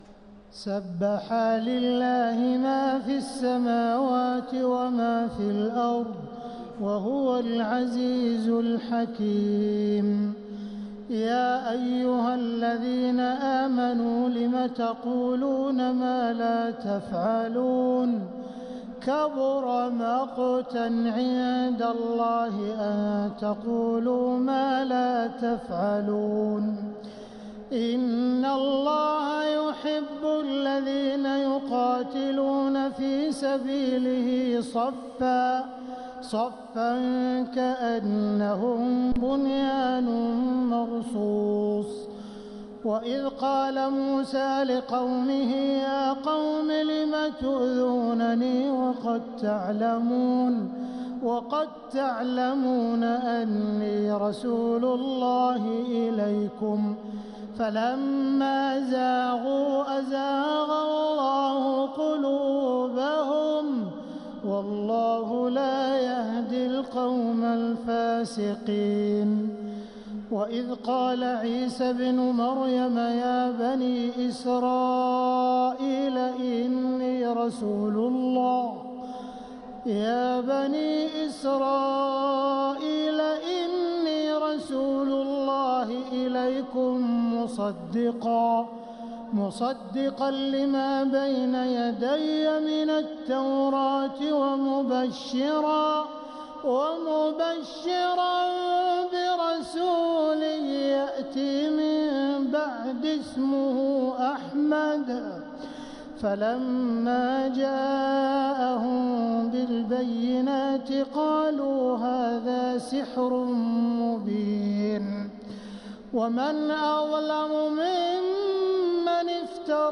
سورة الصف | مصحف تراويح الحرم المكي عام 1446هـ > مصحف تراويح الحرم المكي عام 1446هـ > المصحف - تلاوات الحرمين